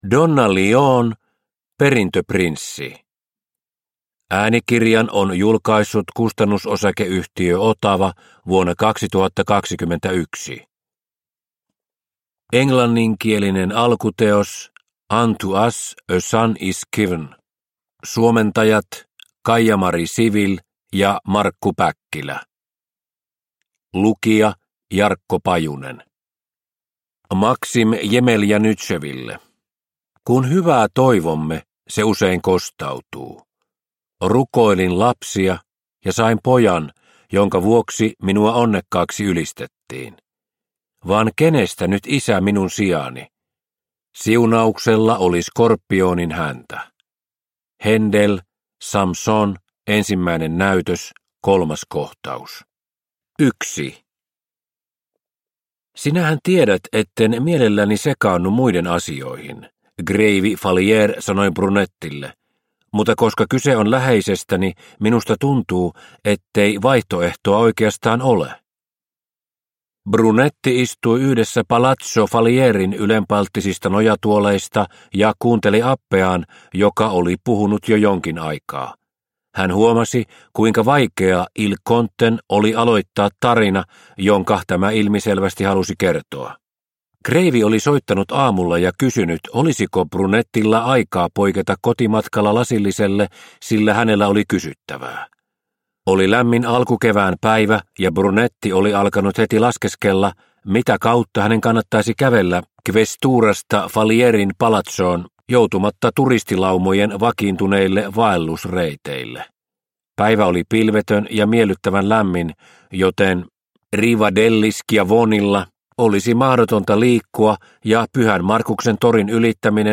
Perintöprinssi – Ljudbok – Laddas ner